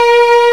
Index of /m8-backup/M8/Samples/Fairlight CMI/IIX/STRINGS1
VIOLSUS.WAV